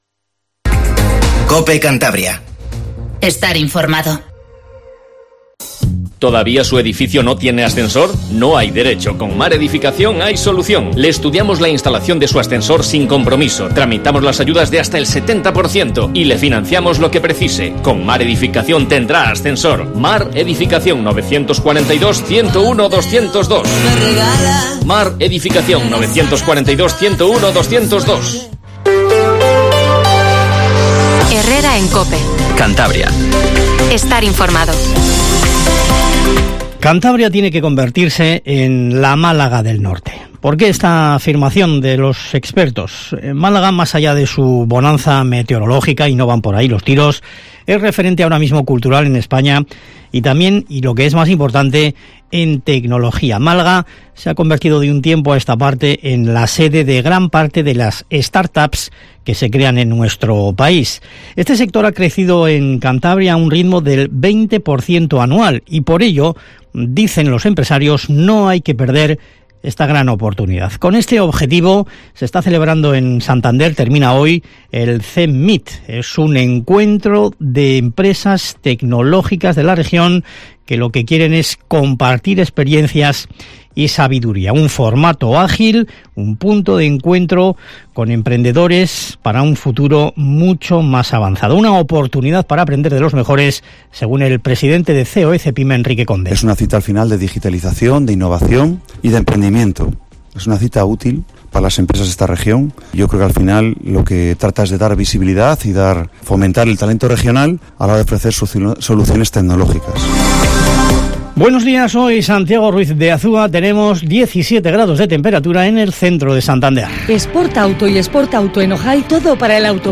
Informativo HERRERA en COPE CANTABRIA 08:24